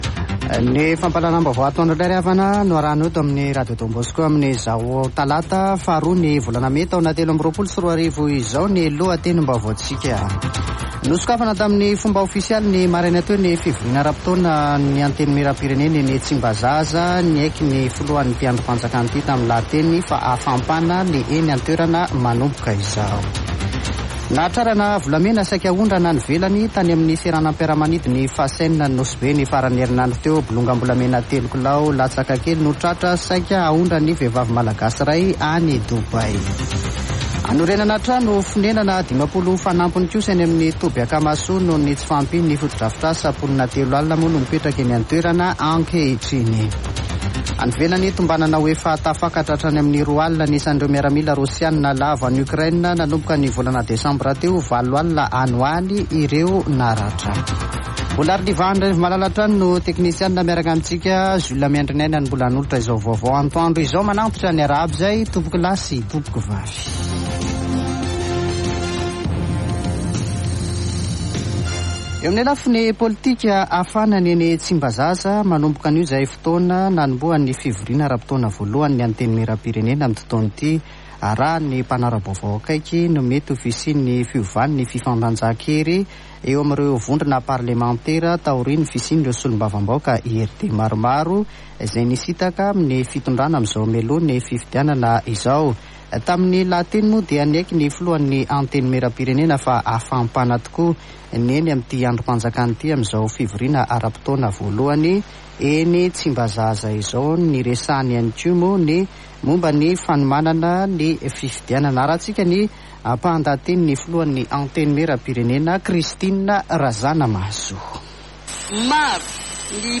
[Vaovao antoandro] Talata 02 mey 2023